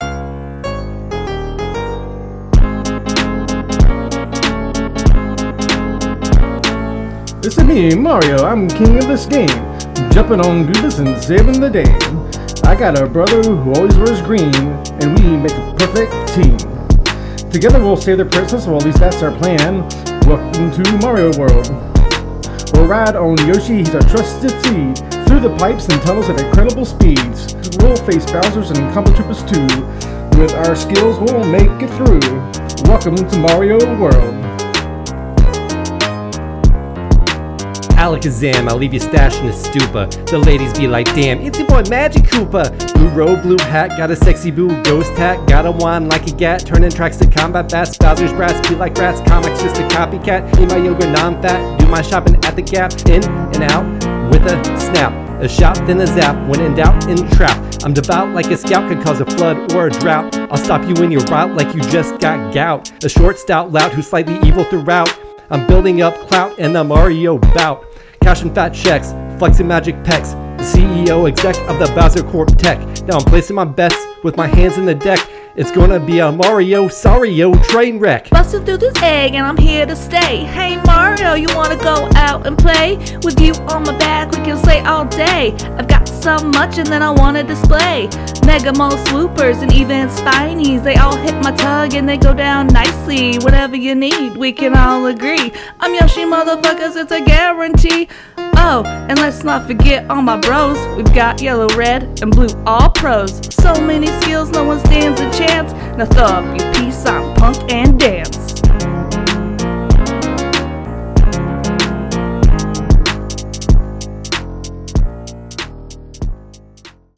Rap from Episode 53: Super Mario World – Press any Button
Super-Mario-World-Rap.mp3